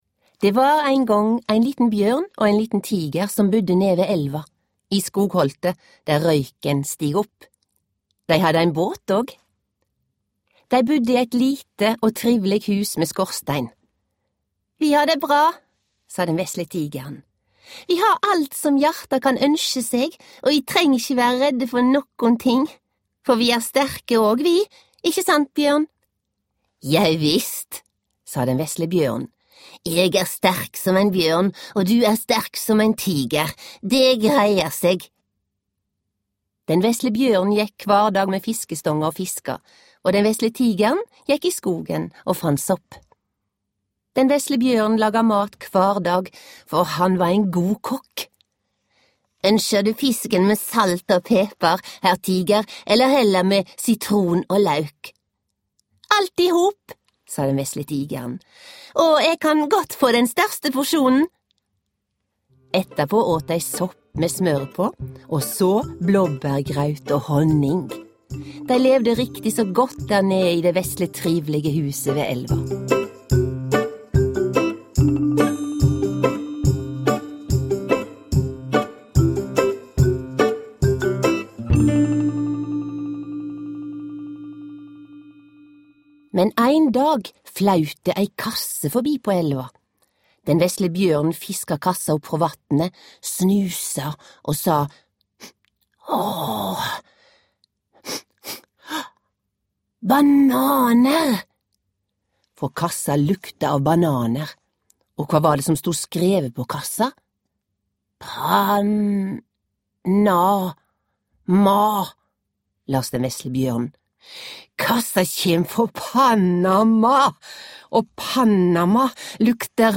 Med tigeren og bjørnen til Panama (lydbok) av Janosch